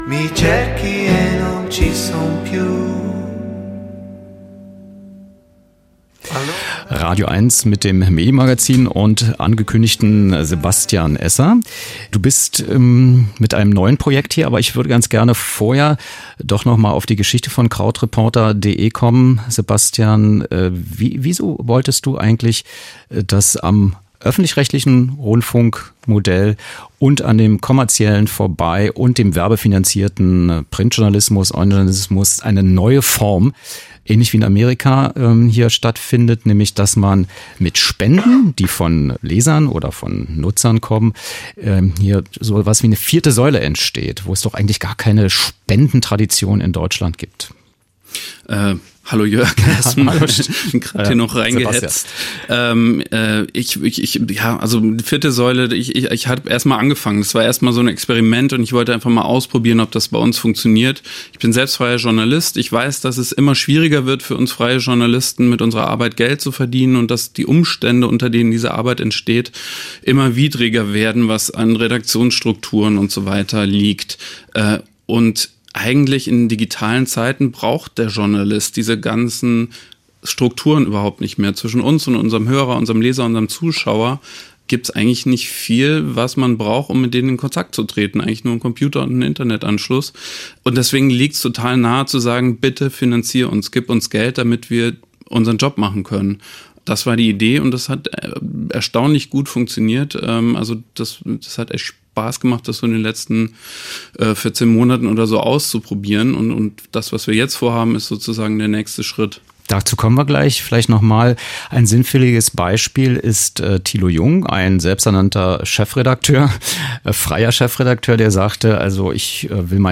Wo: Medienstadt Babelsberg, radioeins-Sendestudio